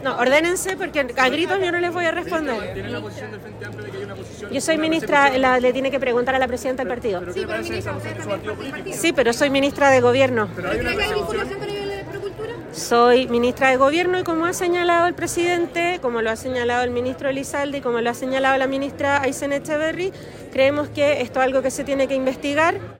Frente a este escenario, este jueves, en medio de un desayuno de la Cámara Chilena de la Construcción, la ministra de la Mujer, Antonia Orellana, uno de los rostros del Frente Amplio en el comité político de ministros y una voz importante al interior de la colectividad, optó por el silencio.